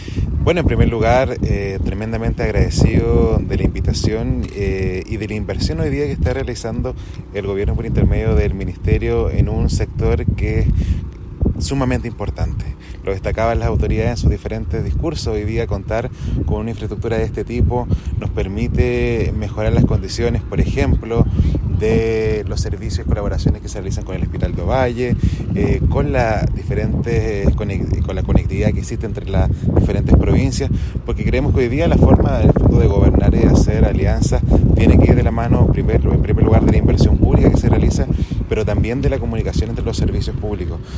Por su parte, el alcalde de Ovalle, Jonathan Acuña valoró la obra de conservación de este importante punto provincial, señalando que está
Alcalde-Ovalle-Jonathan-Acuna.mp3